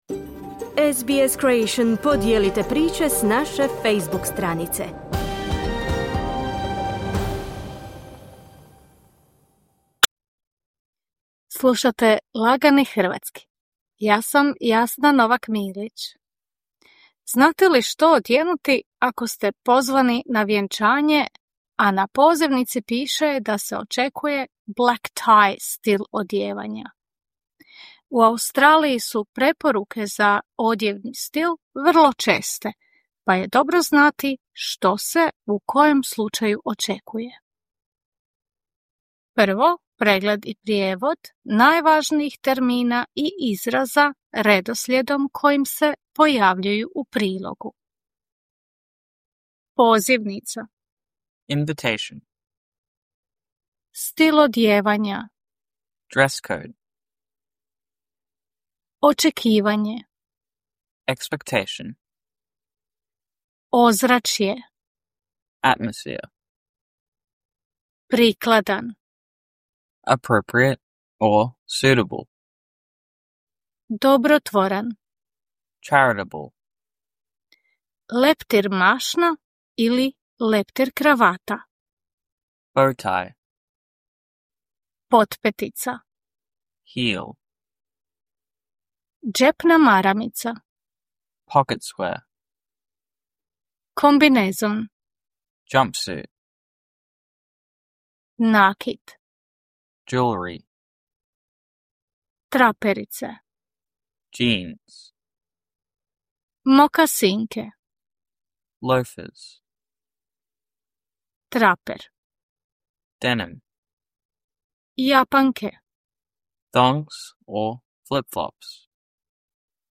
This is Easy Croatian, a podcast by SBS Croatian and the Croatian Studies Centre at Macquarie University, designed for learners who want to improve their Croatian. The content is presented in simple language, with shorter sentences and a slower pace. Before the feature, you will hear key vocabulary and expressions with English translations.